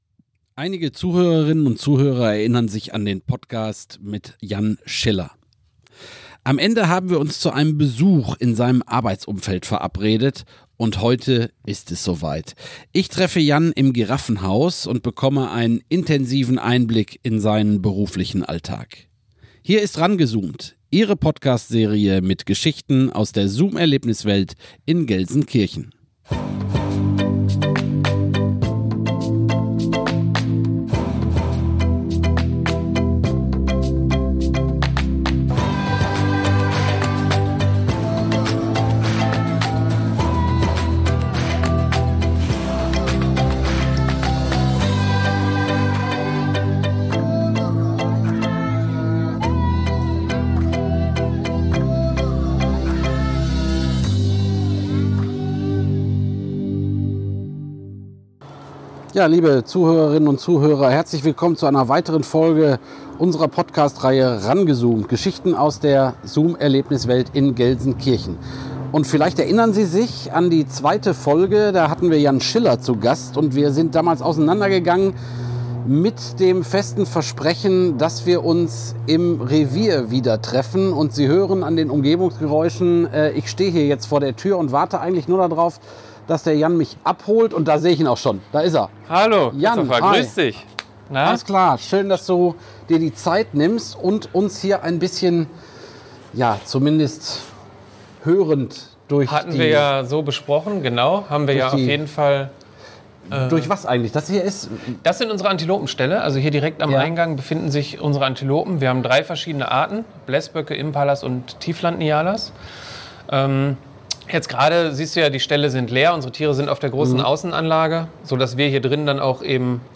Neben den Rothschildgiraffen haben hier auch verschiedenen Antilopen, Stachelschweine und Zwergmangusten ihren Innenbereich. Auf dem Rundgang durch das Haus gibt es einen intensiven Einblick in die Stallungen, die Futterküche und auch in den großen Innenbereich der Giraffen.